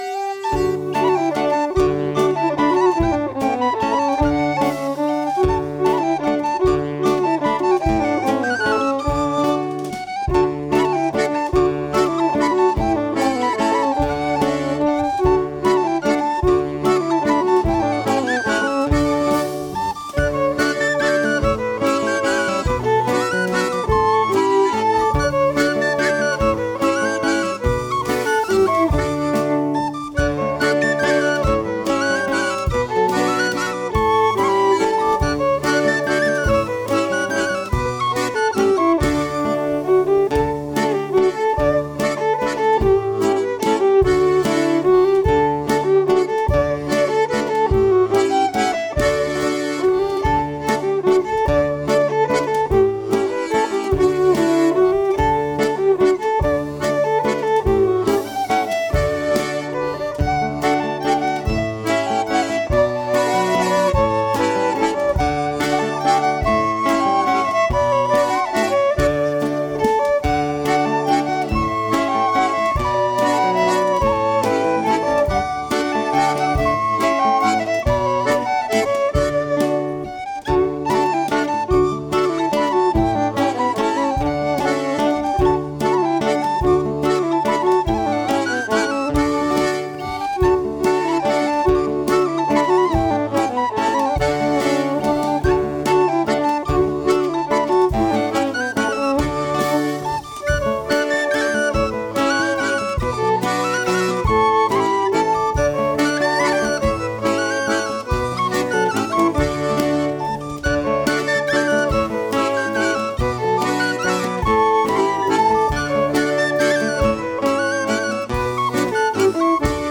Voici quand même un enregistrement en "live" de cette mazurka, jouée par mon groupe Draft Company en 2017. Elle est jouée dans son tempo traditionnel, mais, pour la petite histoire, j'ai une fois entendu un groupe irlandais jouer une mazurka sur un tempo d'enfer, avec des danseurs.
Auteur : Traditionnel Irlande.